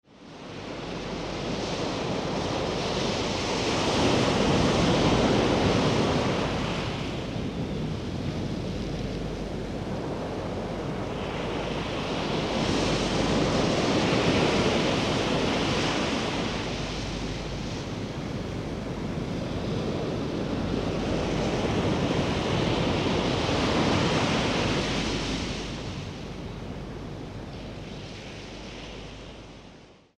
uklidňujících zvuků příboje oceánu